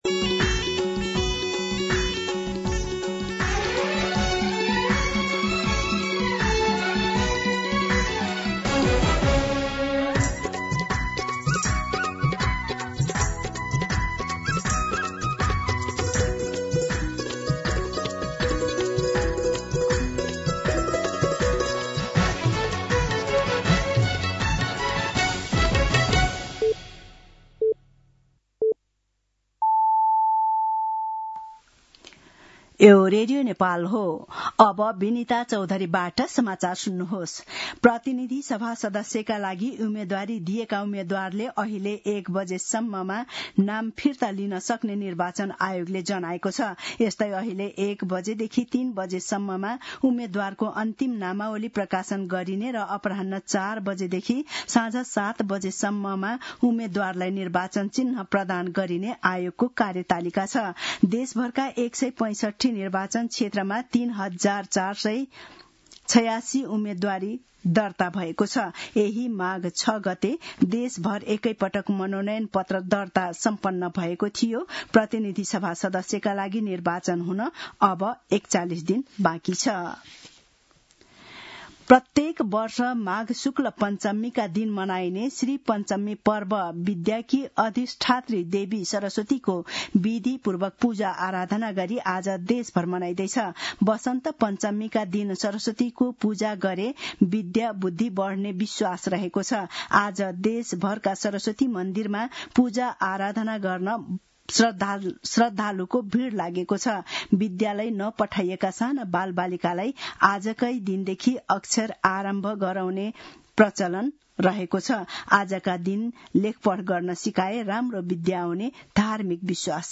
दिउँसो १ बजेको नेपाली समाचार : ९ माघ , २०८२